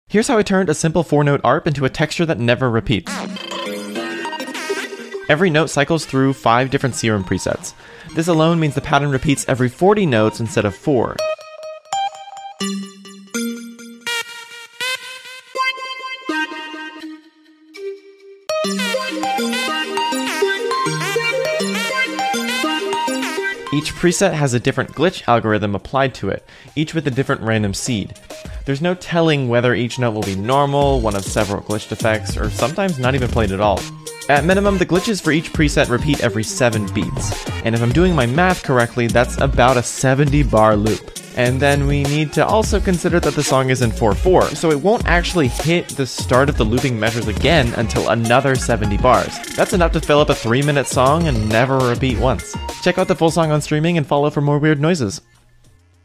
An arp that never repeats sound effects free download